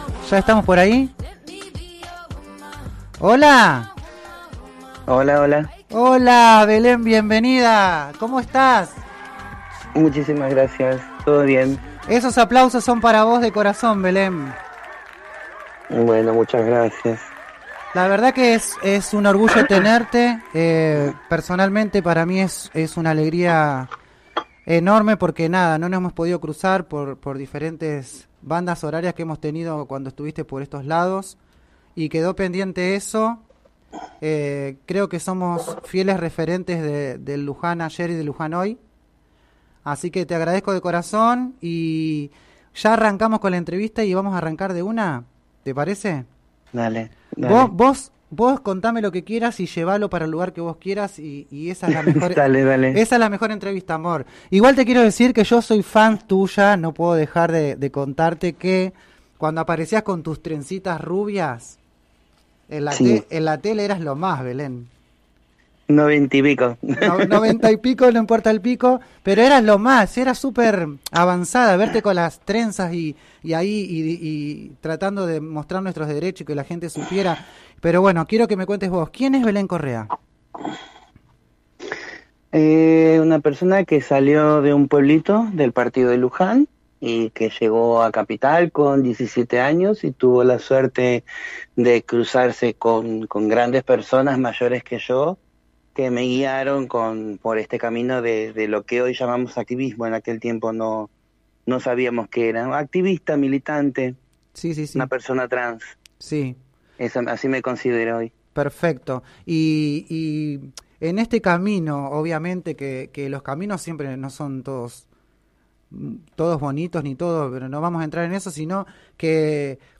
En una charla interesante y distendida, contó cómo a través de un grupo de facebook comenzaba a desarrollarse el Archivo de la Memoria Trans en el cual muchas travestis y trans compartían fotos, reconstruyendo así quienes aparecían en ellas.